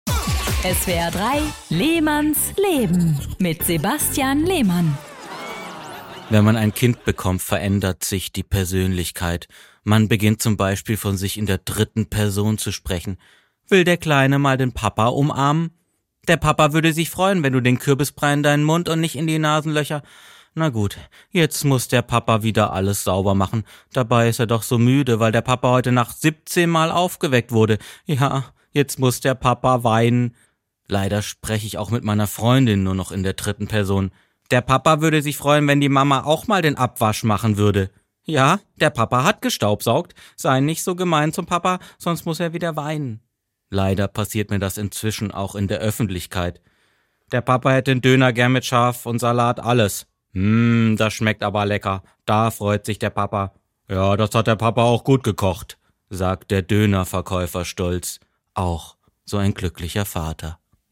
2. Comedy